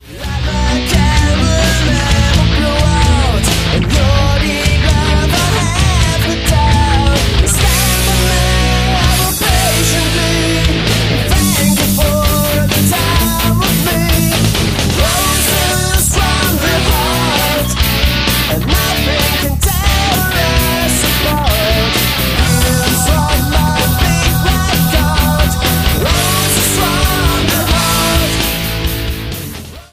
Lead Vocals
Bass, Vocals
Gitarre, Vocals
Drums, Vocals